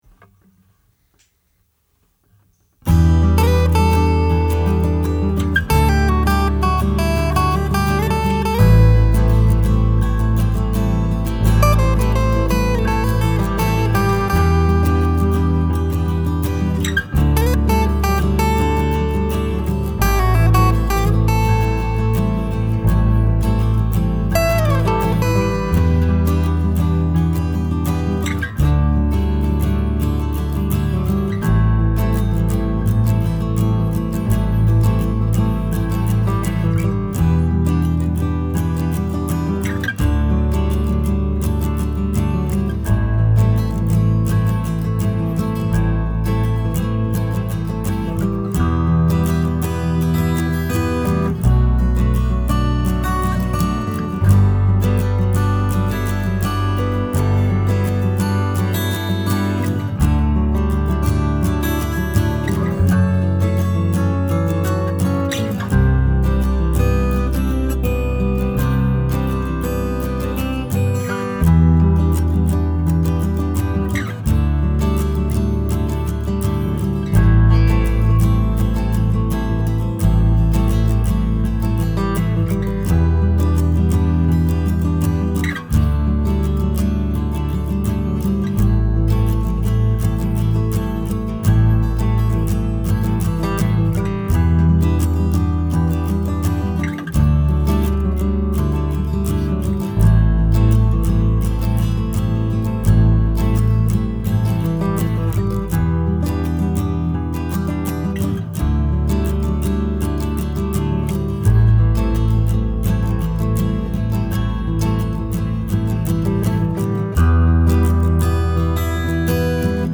The track for today will (someday) have vocals and some other niceties, but I feel that it works pretty well as an instrumental.
For the past few months I have been all over the place stylistically and in this instance I am back in my acoustic guitar comfort zone.
original music